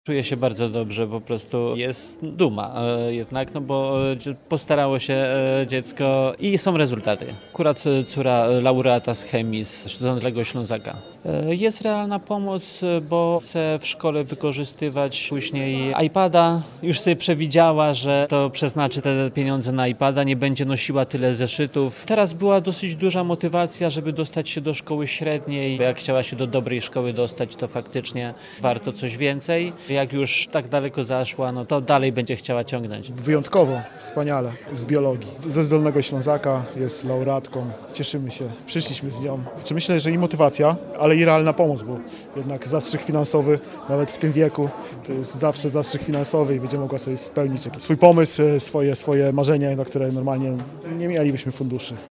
Rodzice nagrodzonych uczniów i uczennic nie kryją radości i dumy.
Wręczenie stypendiów odbyło się podczas uroczystej gali w Liceum Ogólnokształcącym nr XIV we Wrocławiu (25.09.2025 r.).